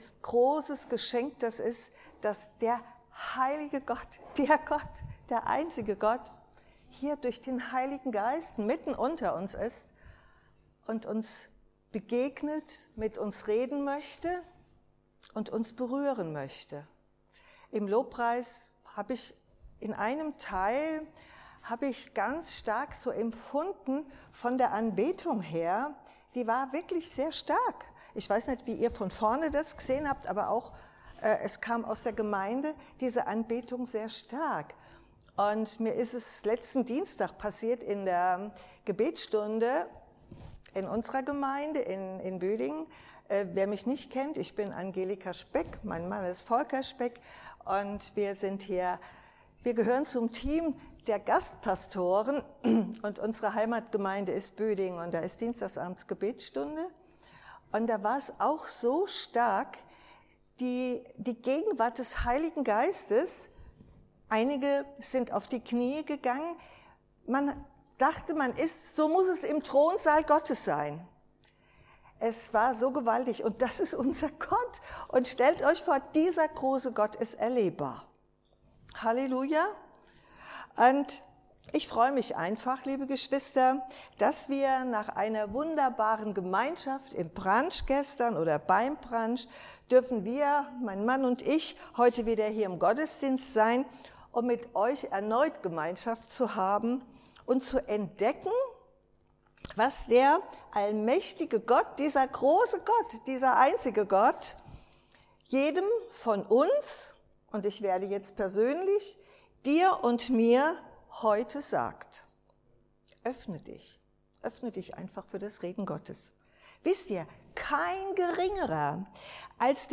5 -7 Dienstart: Predigt Erinnerung an die unverdiente Gnade Jesu.